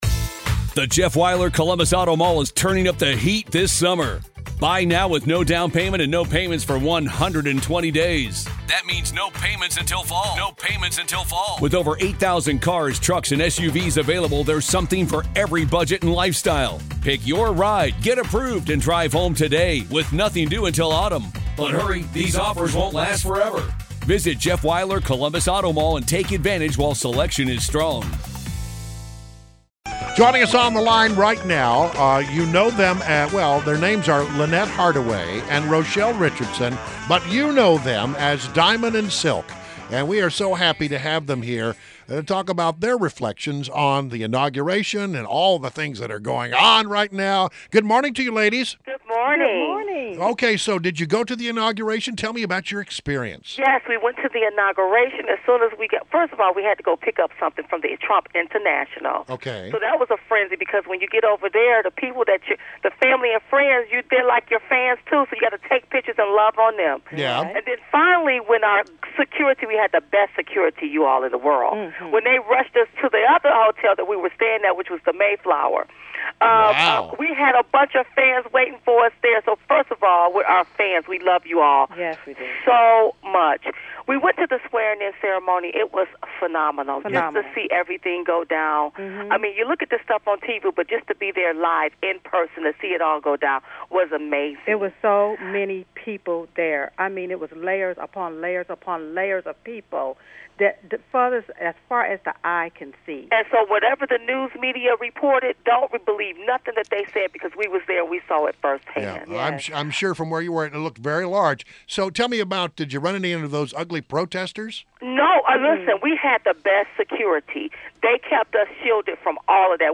WMAL Interview - DIAMOND and SILK - 01.23.17